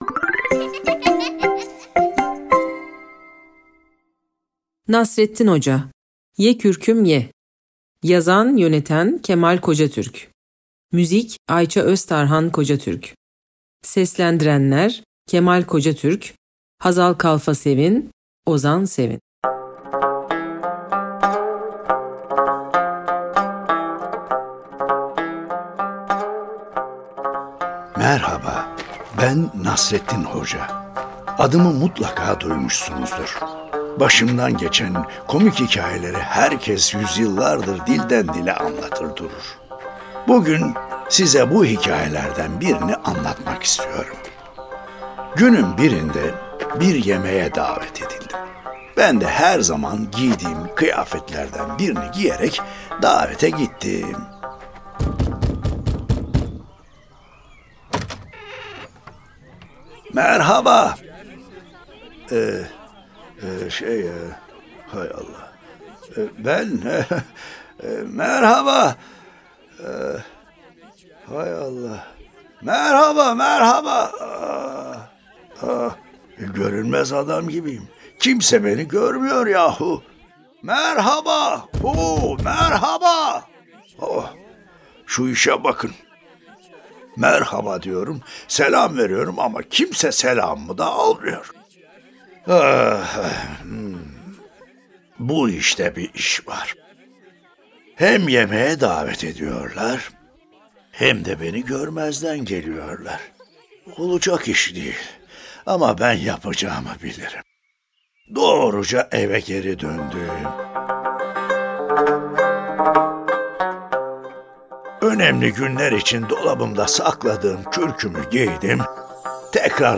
Nasreddin Hoca: Ye Kürküm Ye Tiyatrosu